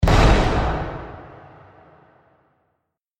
جلوه های صوتی
دانلود آهنگ بمب 1 از افکت صوتی اشیاء
دانلود صدای بمب 1 از ساعد نیوز با لینک مستقیم و کیفیت بالا